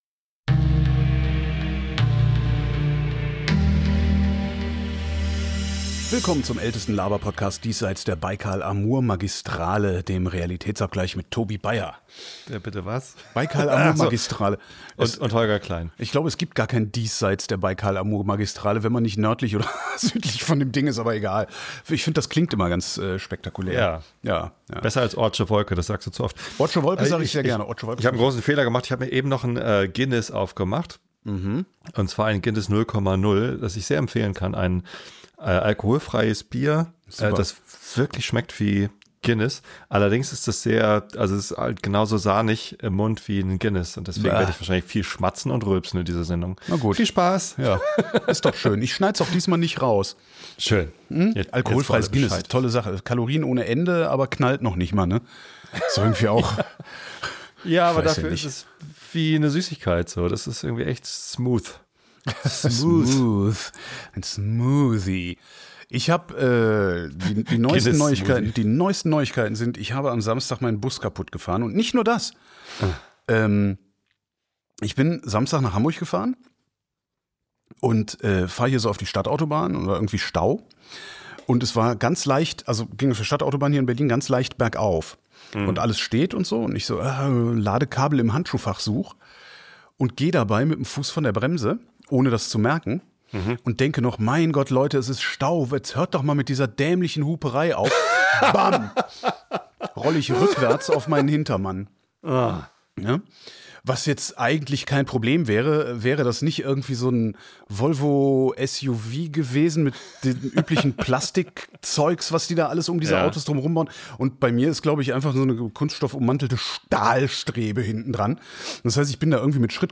Der Laberpodcast.